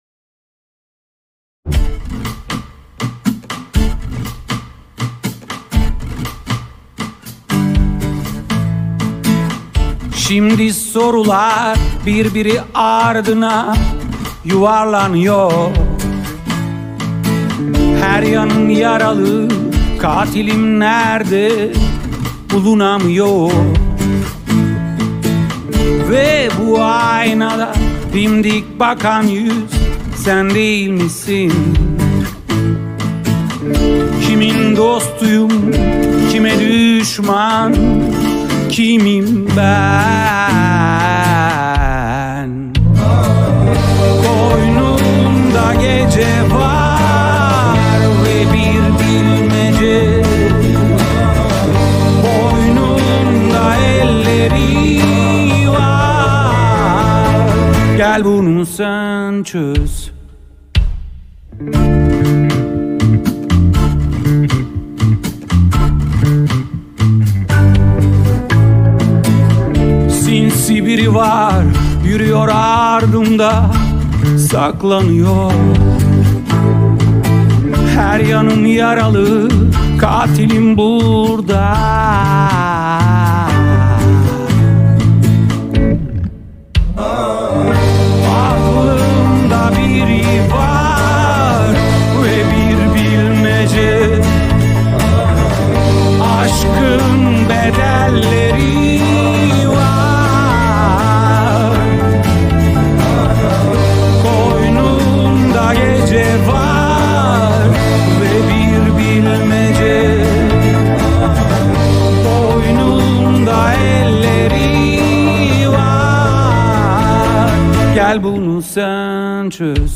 dizi müziği, duygusal hüzünlü enerjik şarkı.